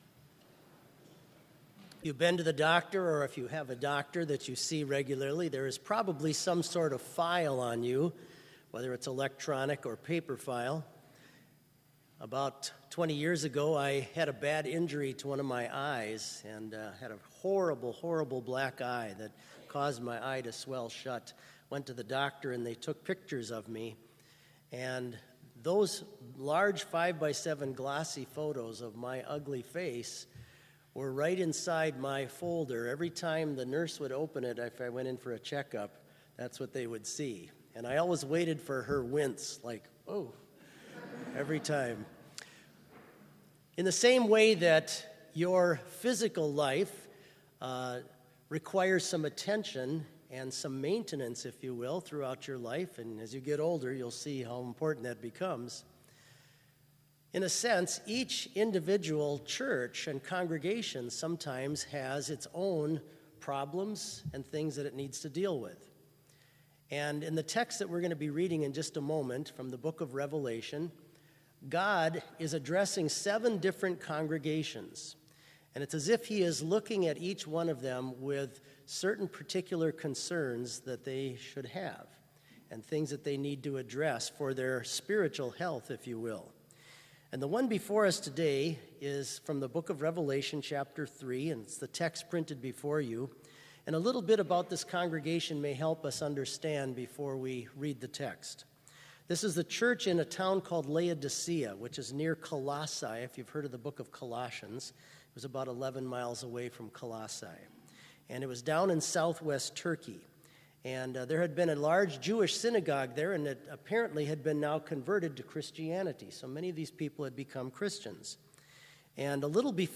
Complete service audio for Chapel - October 25, 2019